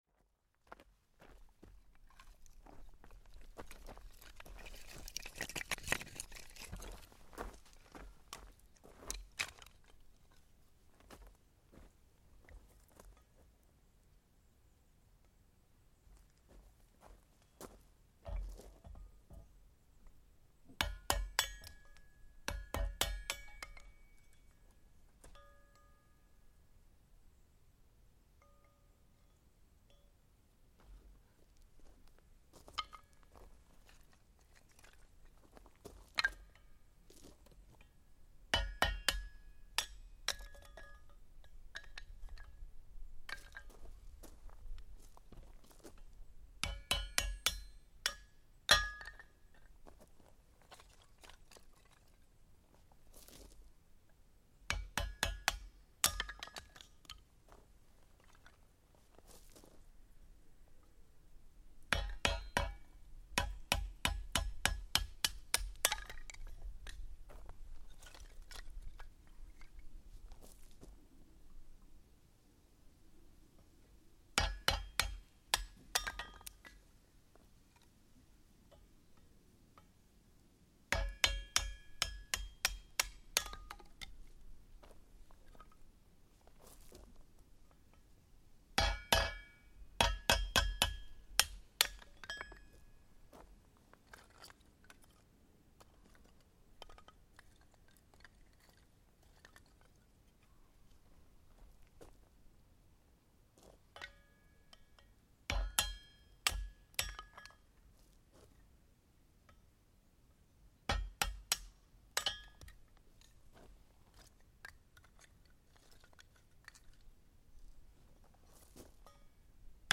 Chopping firewood
The sound of chopping firewood outdoors using a hammer and a firewood chopping frame, in preparation for outdoor open fire cooking next to a lake on a hot July day in Kintbury, England.